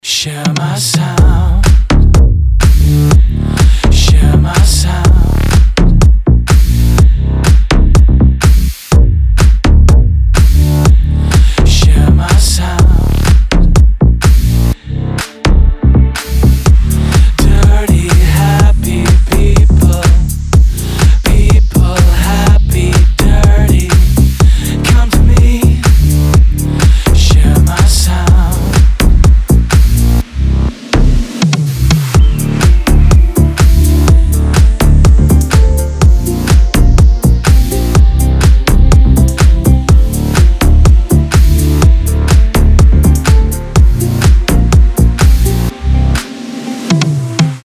мощные басы
чувственные
Brazilian bass
house
клубнячок